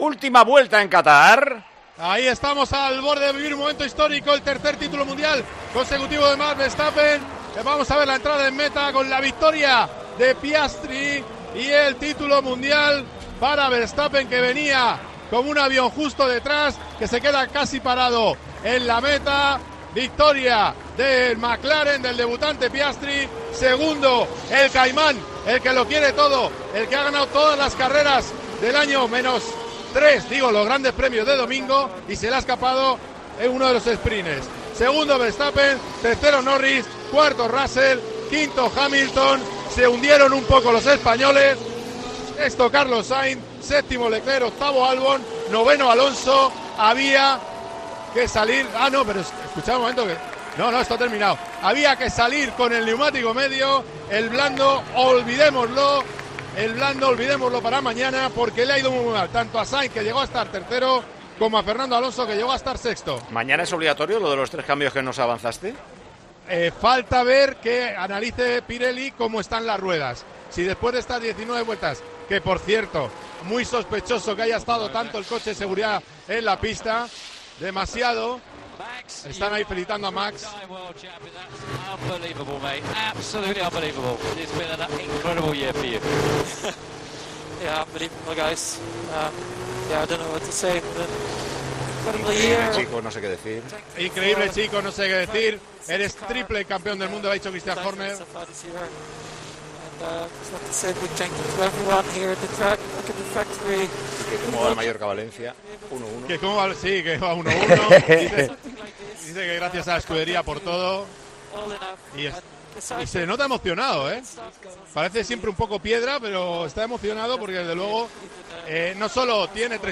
así te lo hemos contado en Tiempo de Juego con la narración